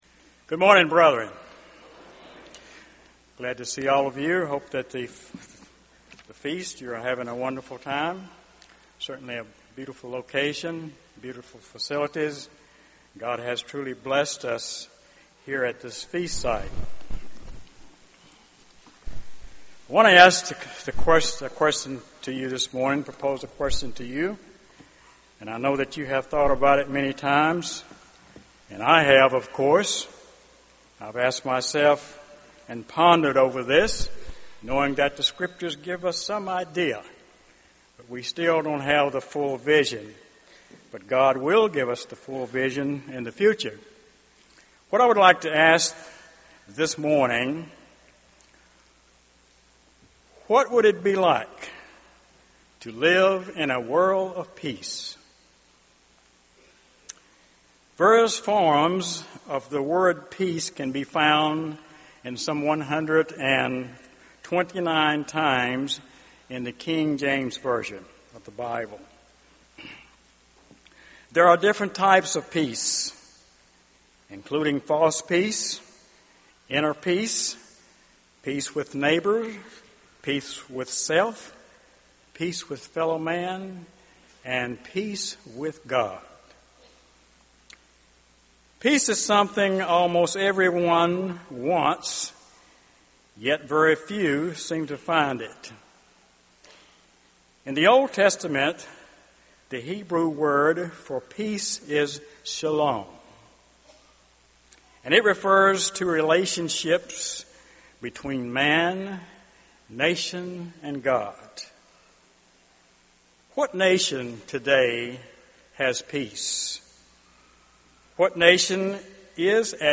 This sermon was given at the Jekyll Island, Georgia 2012 Feast site.